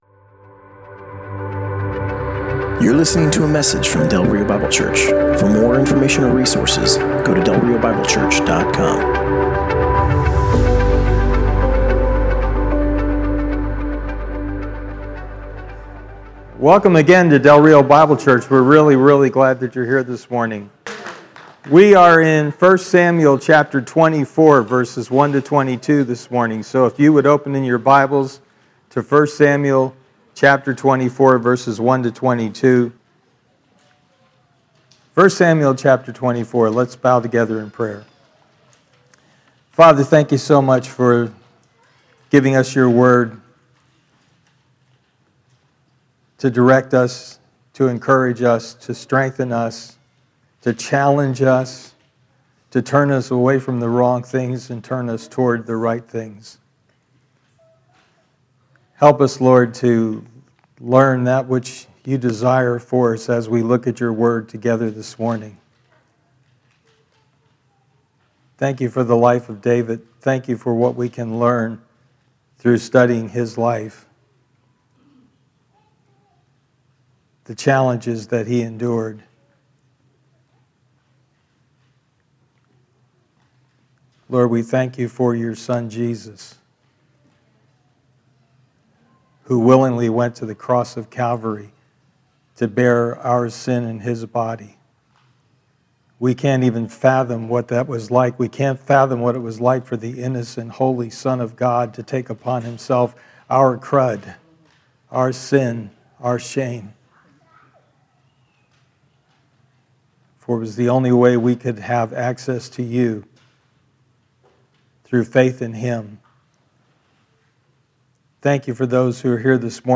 Passage: 1 Samuel 24: 1-22 Service Type: Sunday Morning